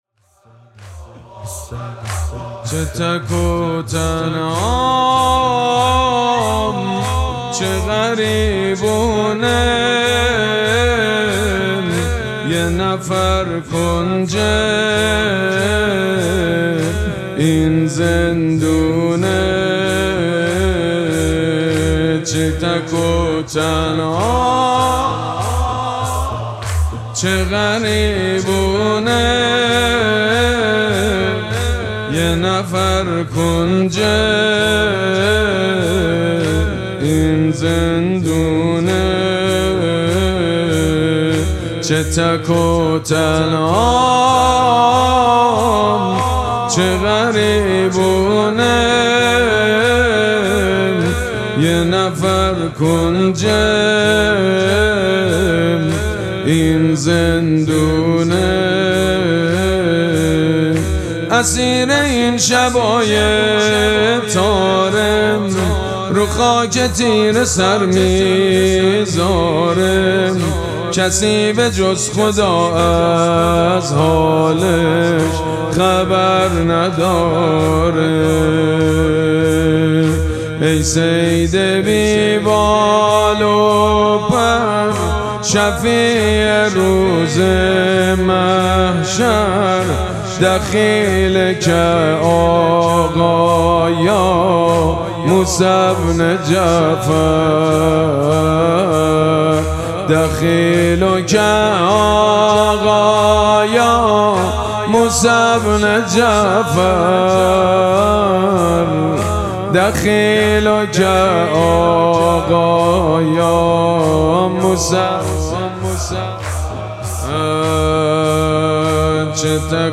مراسم مناجات شب پنجم ماه مبارک رمضان
مداح
حاج سید مجید بنی فاطمه